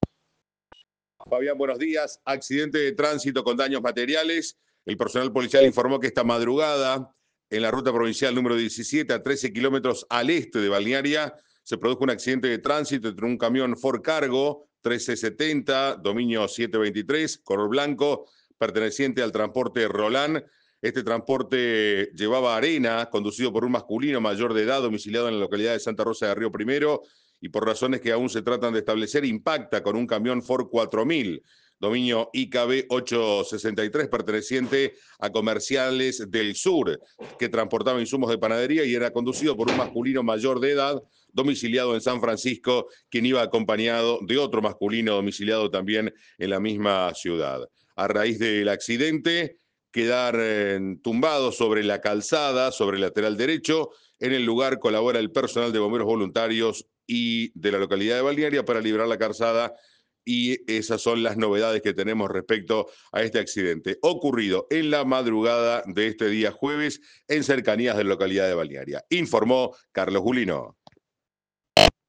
DESDE BALNEARIA